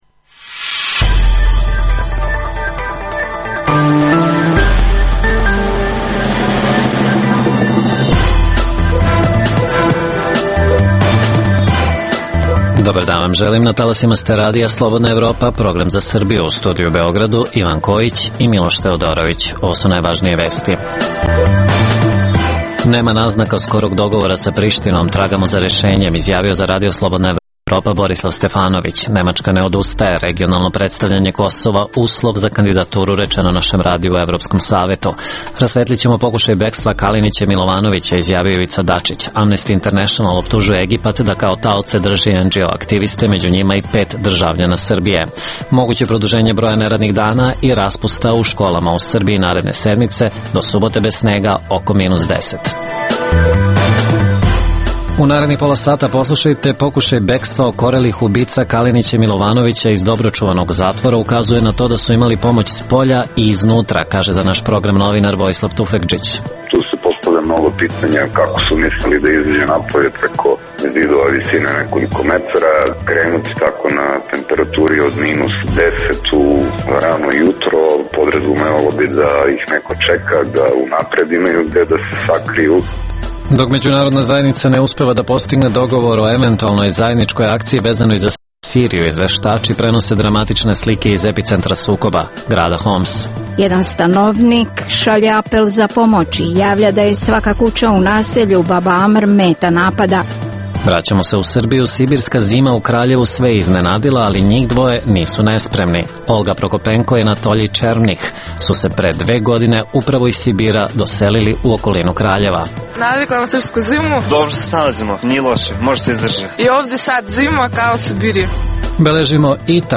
Kakve su šanse za dogovor Prištine i Beograda – procenjuje Borislav Stefanović. Sa lica mesta izveštava i dopisnik RSE.